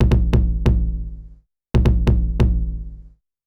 Index of /90_sSampleCDs/Best Service ProSamples vol.54 - Techno 138 BPM [AKAI] 1CD/Partition C/SHELL CRASHE
TOMTOMTOM -L.wav